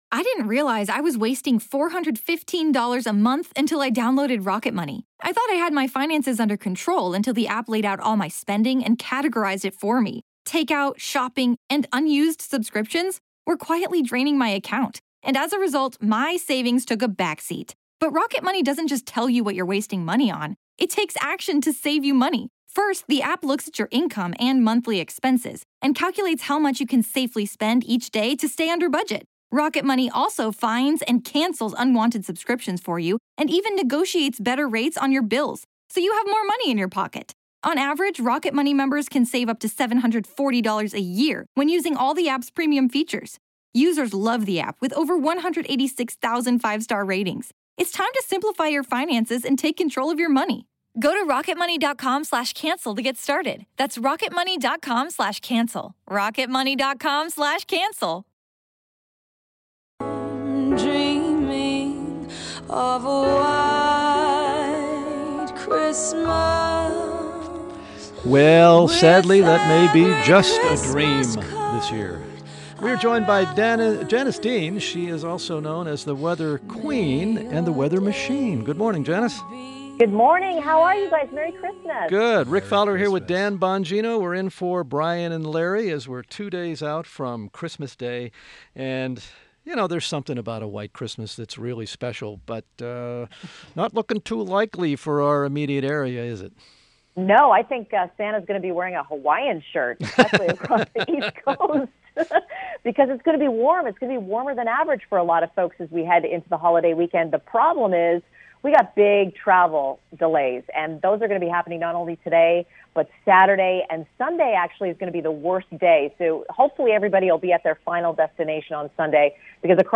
WMAL Interview - JANICE DEAN - 12.23.16